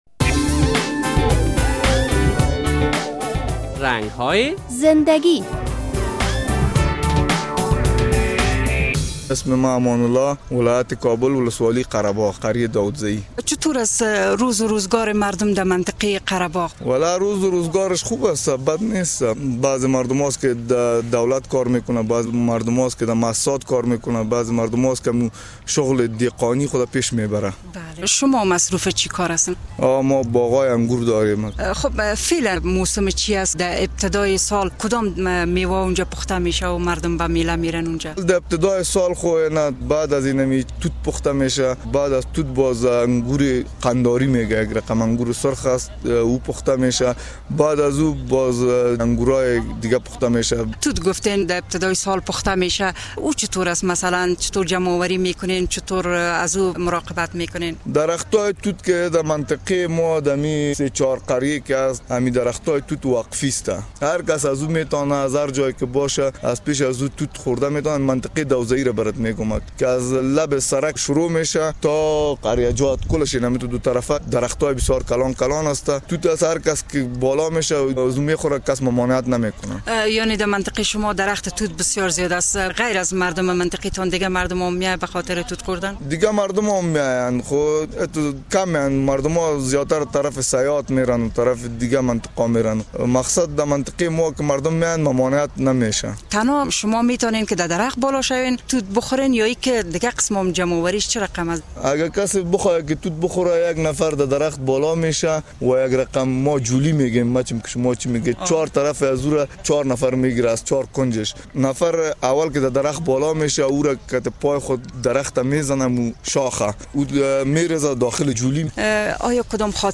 در این برنامهء رنگ های زندگی با یک تن از باشنده های ولسوالی قره باغ صحبت شده و در مورد اوضاع امنیتی و کار و بار زارعین در این ولسوالی پرسیده شده است.